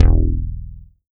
WURST BASS 1.wav